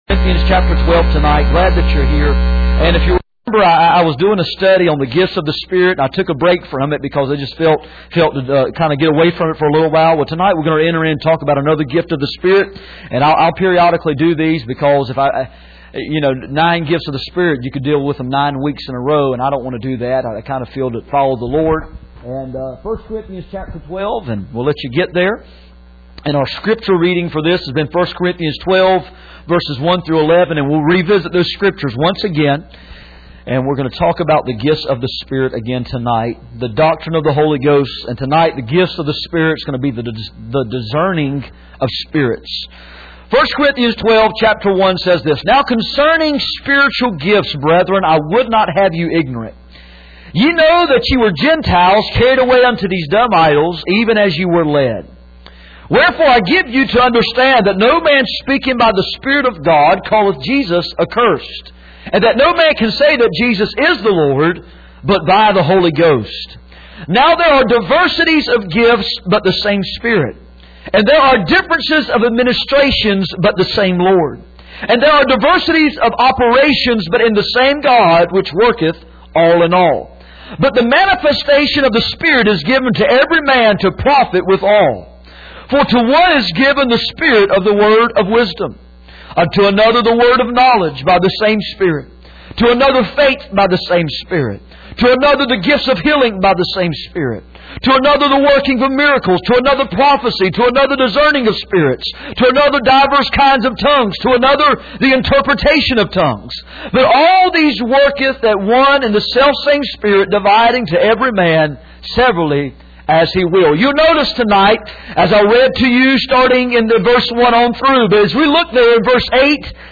None Passage: 1 Corinthians 12:1-11 Service Type: Sunday Evening %todo_render% « Misery